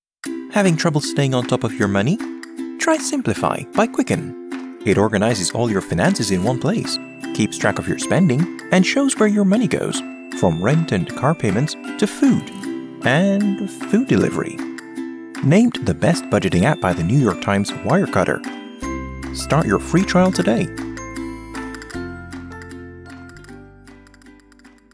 Inglês (jamaicano)
Inglês (Caribe)
Amigáveis
Confiante